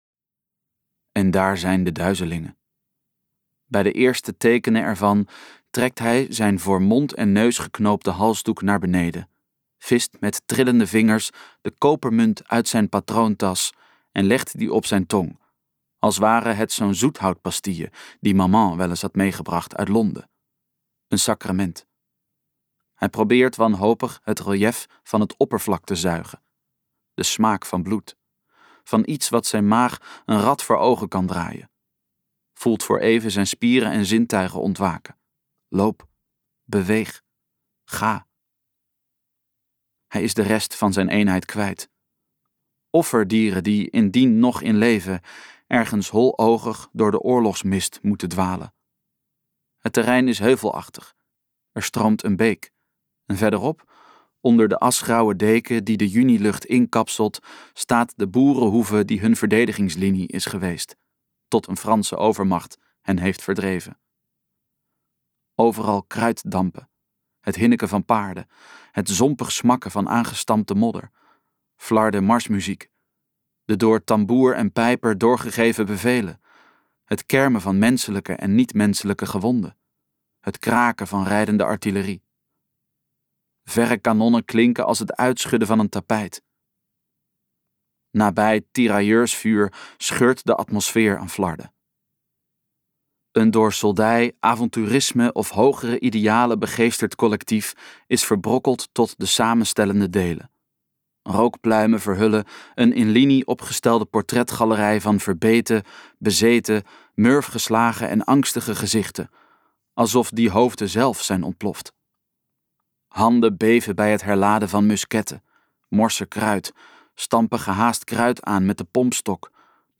Tandenjager luisterboek | Ambo|Anthos Uitgevers